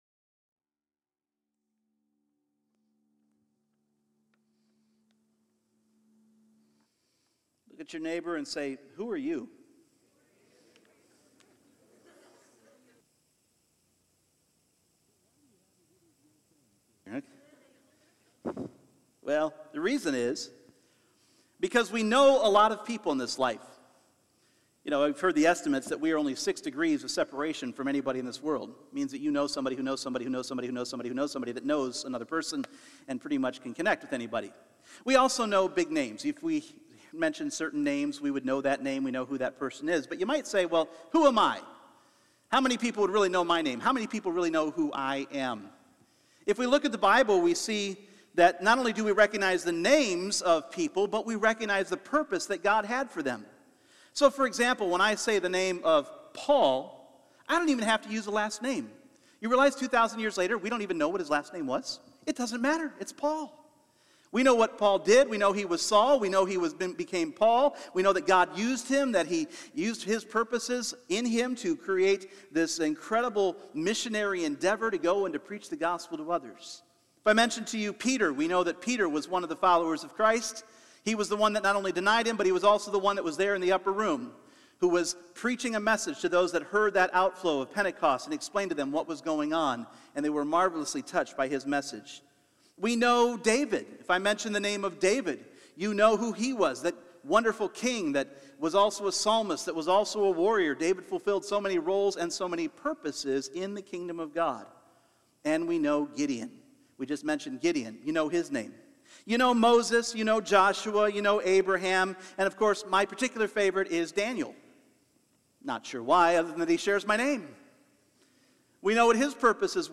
Purpose Service Type: Sunday Morning God has a plan and a purpose for your life no matter how obscure you may seem.